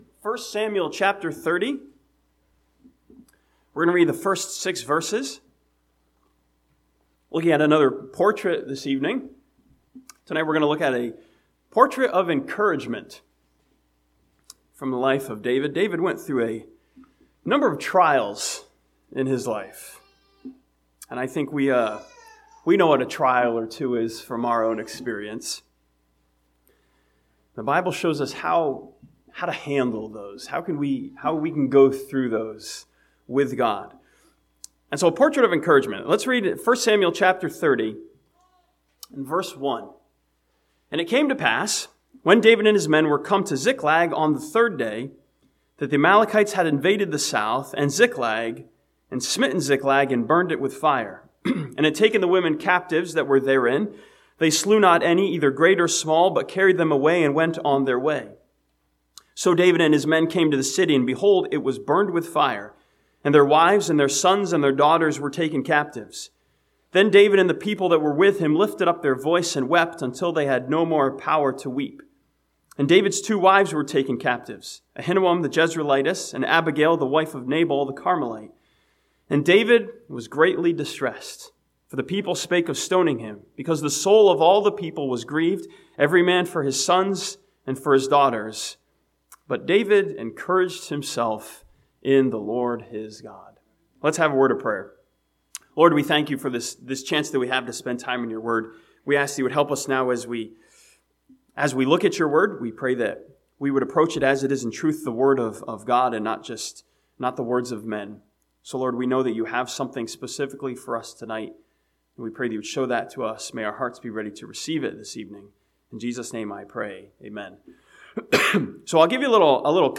This sermon from 1 Samuel 30 takes a look at David during an intense trial as a portrait of encouragement.